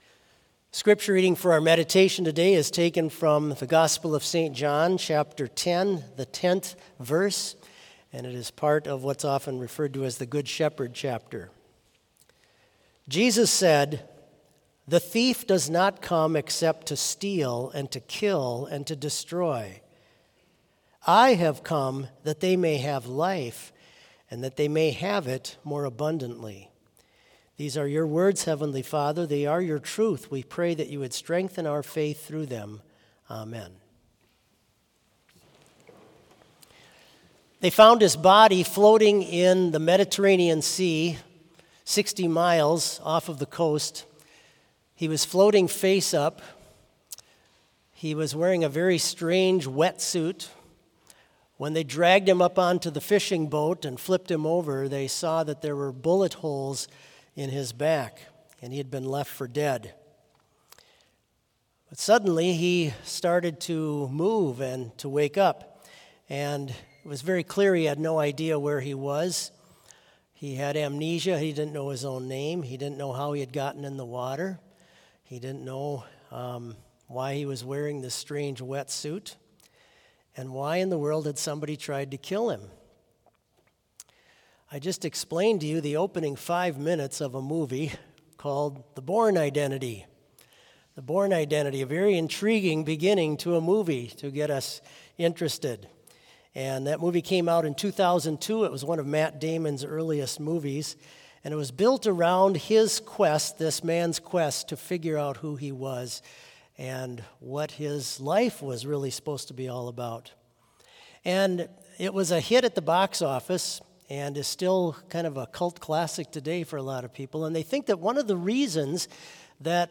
Complete service audio for Chapel - Tuesday, August 20, 2024